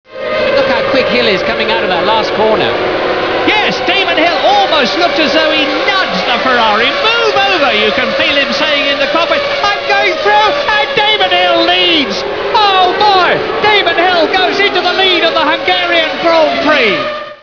Can we ever forgive the failure (at the time) to hear Murray describing Damon in a humble Arrows passing Schumacher at Hungary '97? Here you can relive the full move as it happened